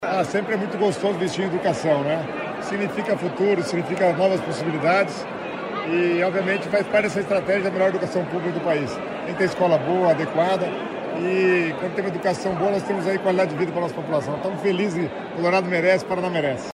Sonora do secretário Estadual das Cidades, Guto Silva, sobre a inauguração do CEEPA de Colorado